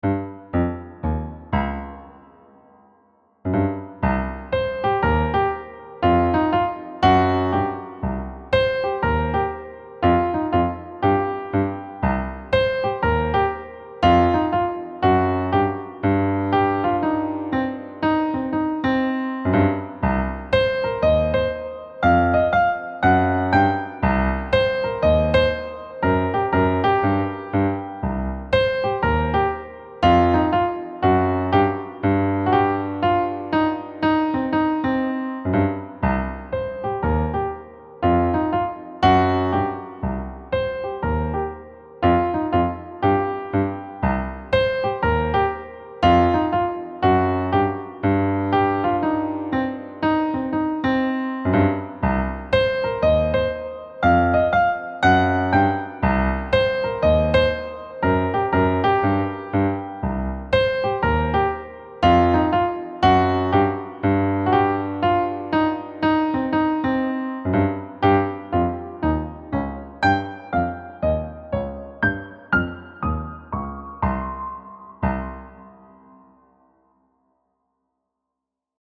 a playful, energetic piano solo
Key: C minor
Time Signature: 4/4 (BPM ≈ 120)